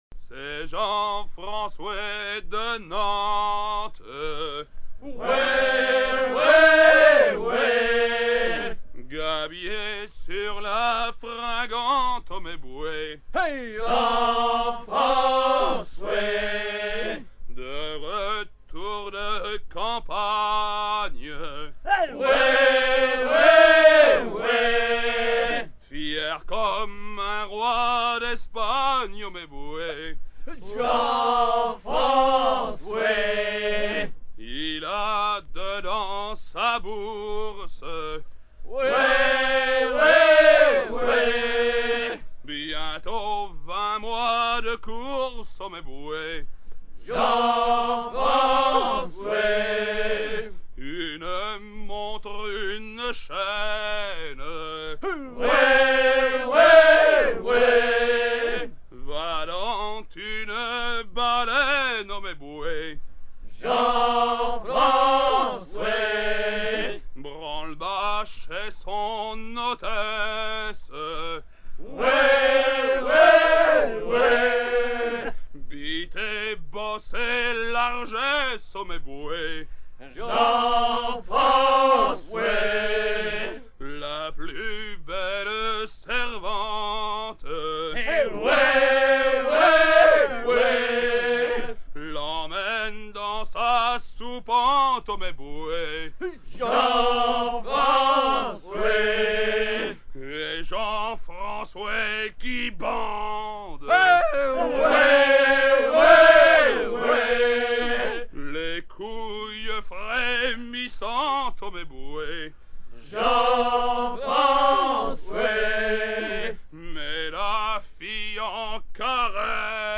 gestuel : à hisser à grands coups
Genre laisse